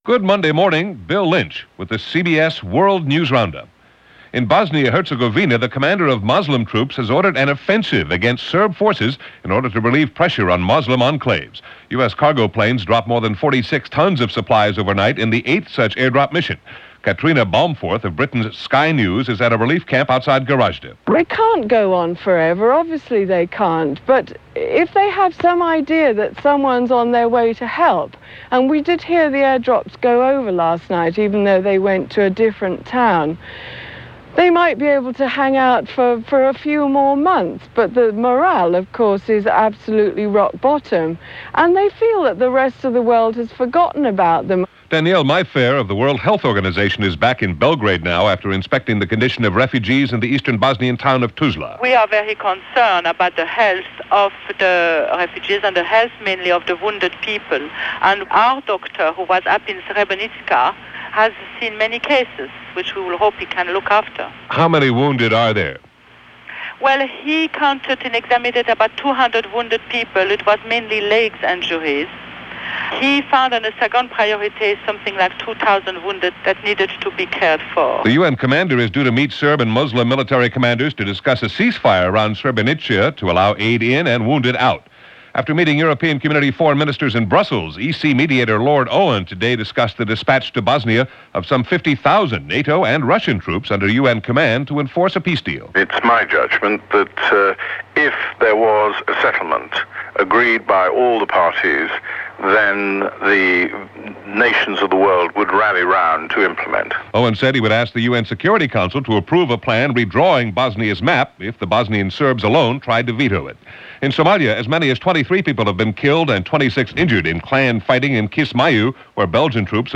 And so much more happened this day – March 8, 1993 as reported by The CBS World News Roundup.